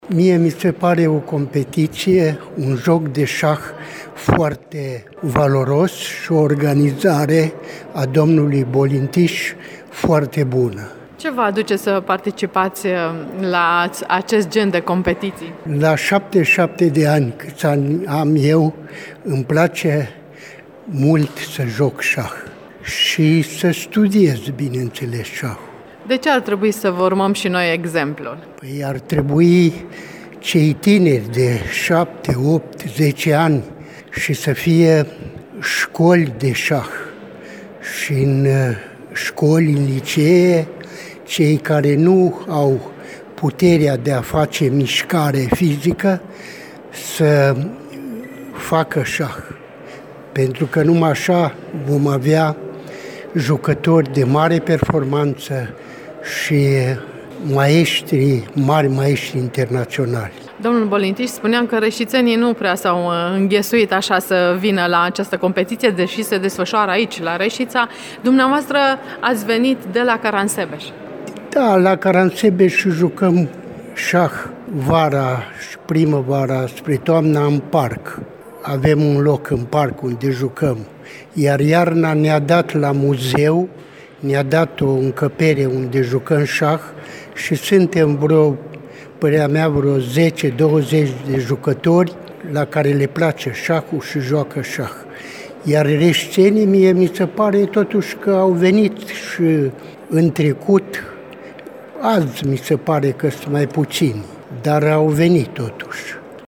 Am dialogat cu cei doi: